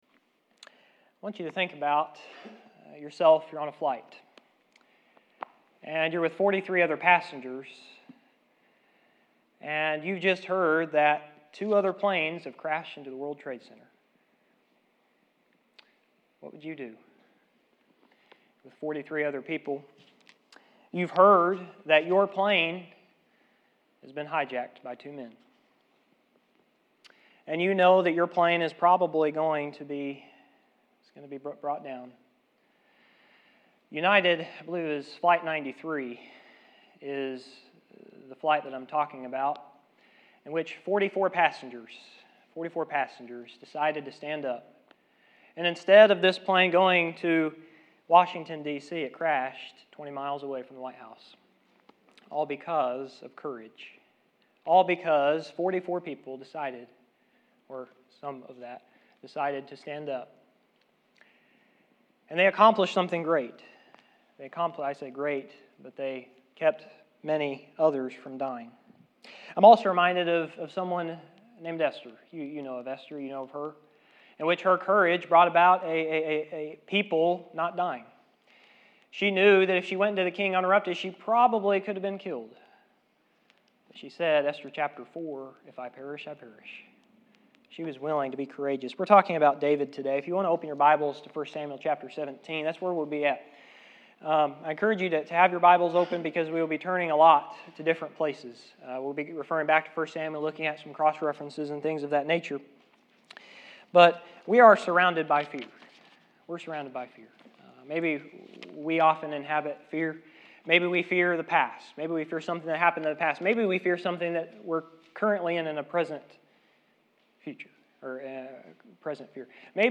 1 Samuel 17:40 Service Type: Seminar Think about yourself on a flight.